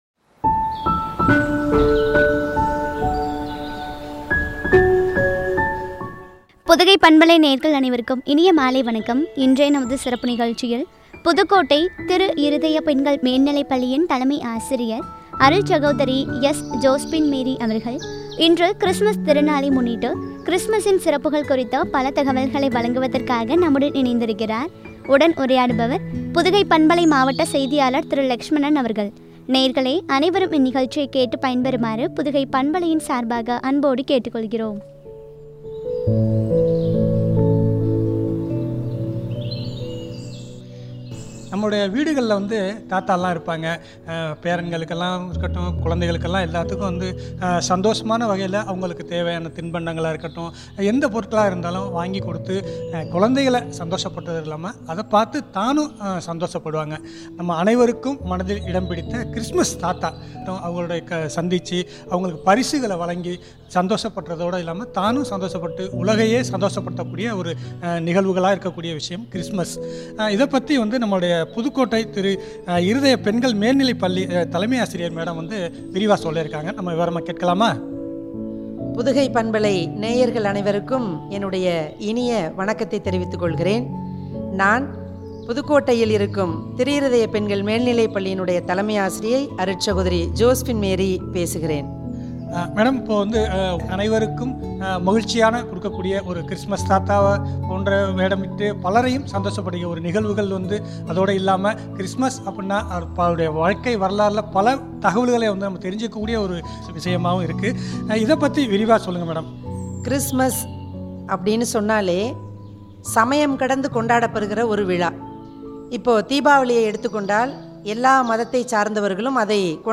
கிருஸ்துமஸ் விழாவின் சிறப்புகள் பற்றிய உரையாடல்.